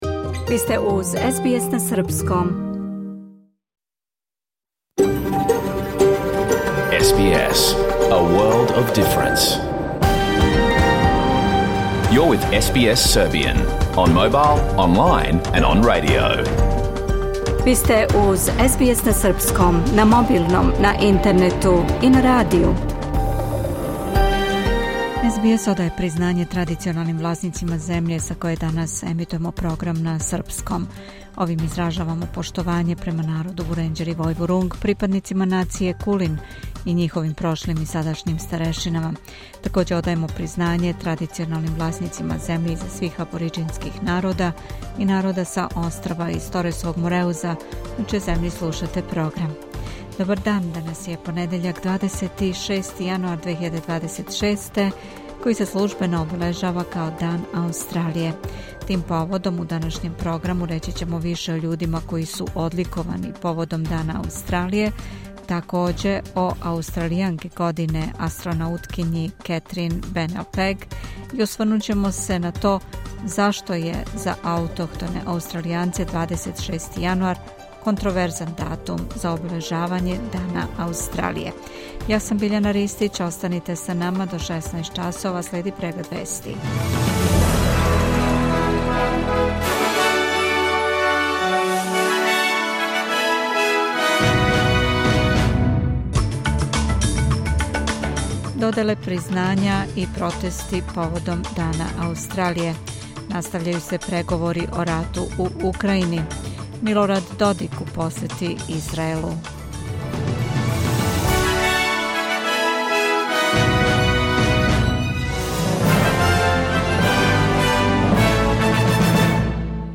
Програм емитован уживо 26. јануара 2026. године
Уколико сте пропустили данашњу емисију, можете је послушати у целини као подкаст.